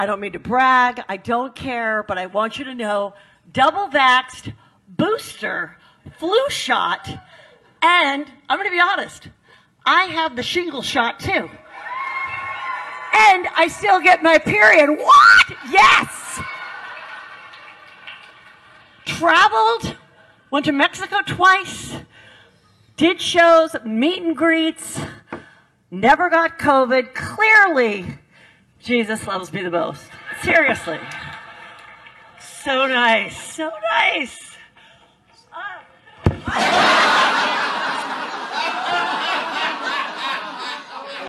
Hochmut kommt vor dem Fall: Kabarettistin Heather McDonald macht sich auf der Bühne über Ungeimpfte lustig